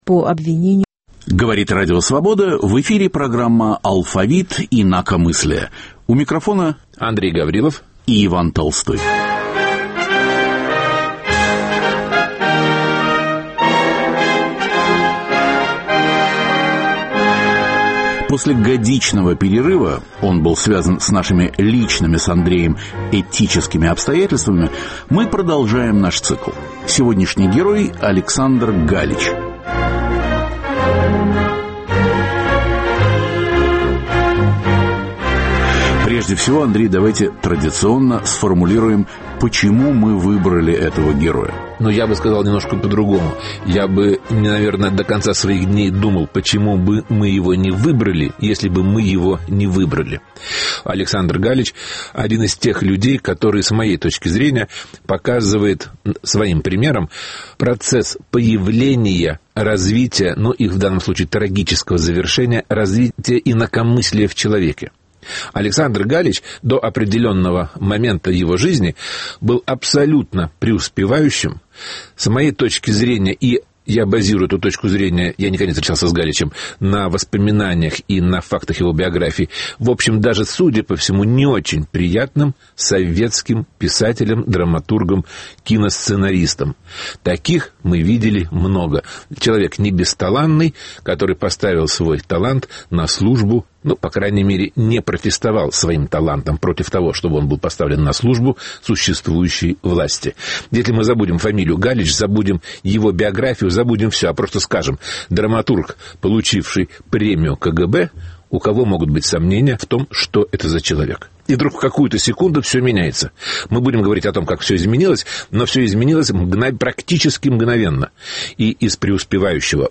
Выпуск посвящен советскому периоду в жизни знаменитого барда. Звучат редкие записи с домашних концертов.